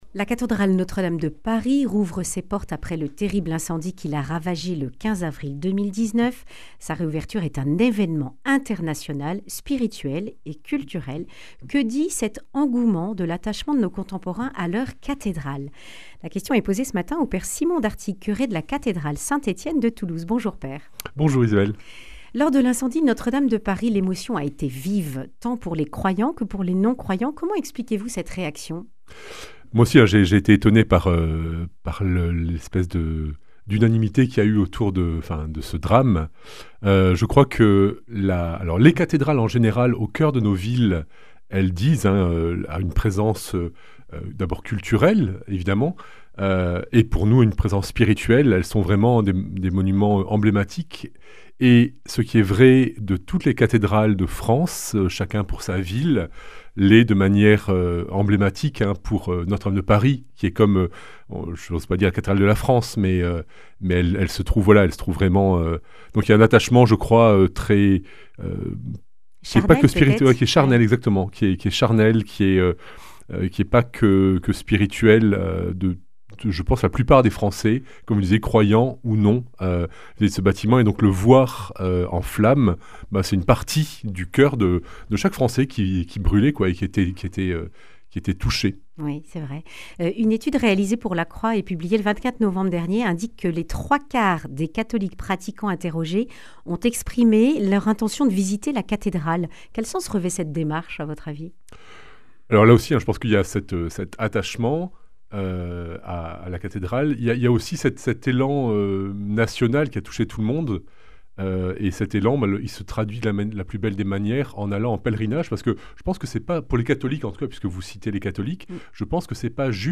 Accueil \ Emissions \ Information \ Régionale \ Le grand entretien \ Que dit l’attachement des Français à leurs cathédrales ?